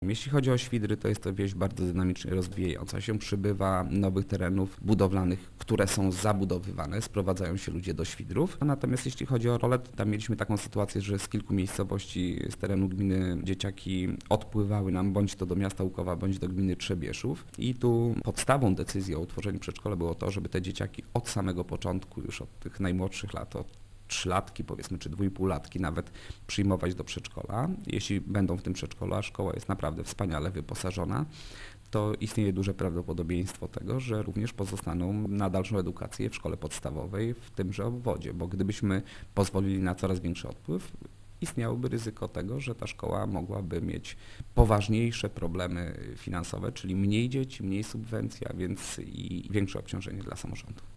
W tym roku szkolnym powstały dwie nowe placówki przy szkołach podstawowych w Rolach i Świdrach, gdzie utworzono zespoły szkół. Wójt Mariusz Osiak mówi, że wybór tych miejscowości nie był przypadkowy: